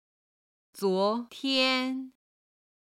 今回は今日の中国語「今天(jīn tiān)」基準に昨日、一昨日(おととい)、一昨昨日(さきおととい)、明日、明後日(あさって)、明々後日(しあさって)の中国語表現をピンインとカタカナ読みに普通語一級の資格を持つ中国人教師の発音を付けて覚える際のコツと共にご紹介します。